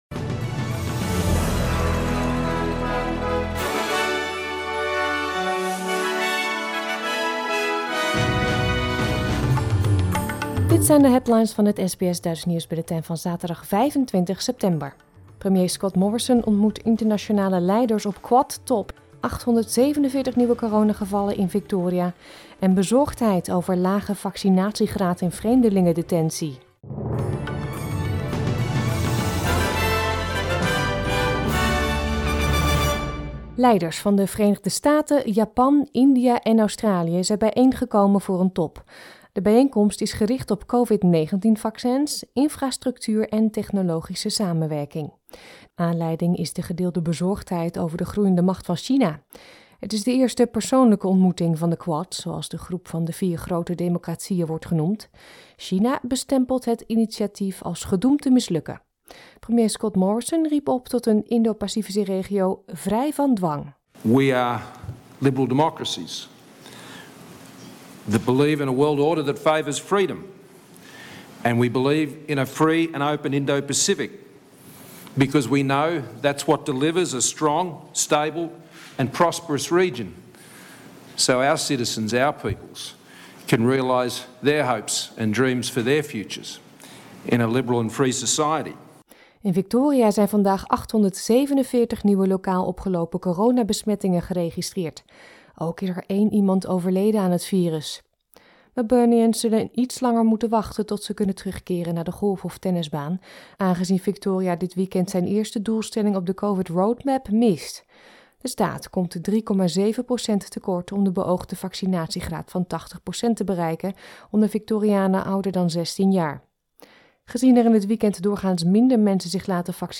Nederlands/Australisch SBS Dutch nieuwsbulletin van zaterdag 25 september 2021